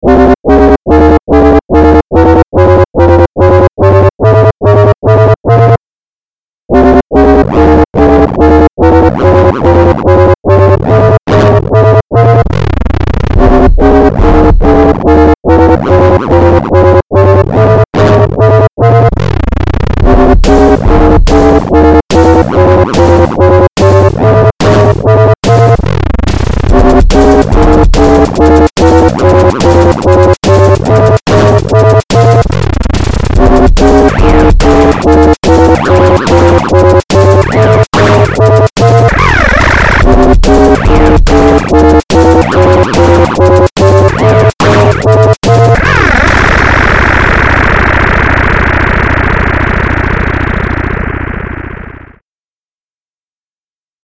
Made in manoloop.